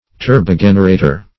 Turbogenerator \Tur`bo*gen"er*a`tor\, n. [See Turbine, and